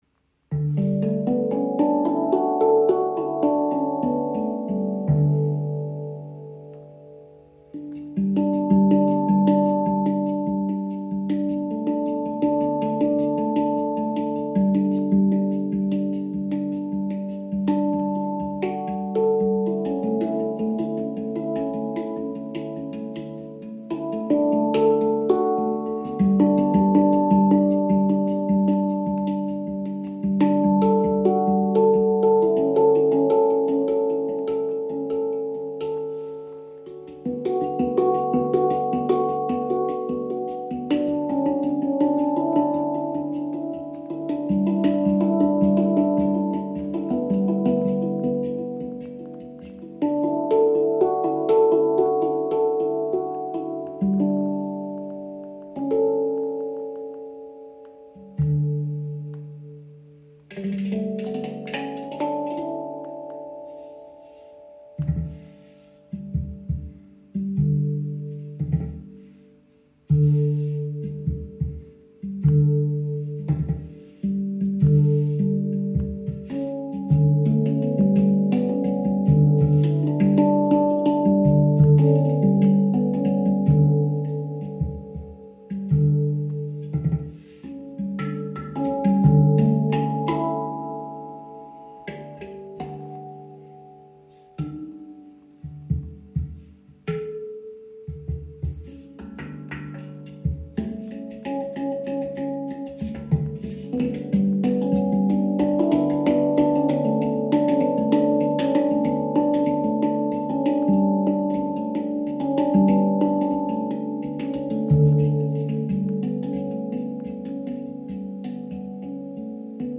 Handpan Chirp | D Ashakiran | 9 Klangfelder – inkl. Rucksacktasche
• Icon D Ashakiran – strahlend frohe Töne (D – G A B C# D E F# A)
Handgefertigte Handpan aus Edelstahl mit präziser Stimmung, lyrischer lydischer Skala und Transporttasche – ideal für Meditation, Jam-Sessions und Klangarbeit.
Sie gehört zu den Dur-Variationen und zeichnet sich durch einen erhöhten vierten Ton aus, was ihr einen charakteristischen, lydischen Klang verleiht. Die Töne D – G A B C# D E F# A erzeugen eine strahlende, fröhliche Klangwelt, die Lebensfreude, positive Energie und Leichtigkeit vermittelt.
Im Spiel entfaltet die Handpan eine klare, lebendige Stimme mit harmonischer Resonanz.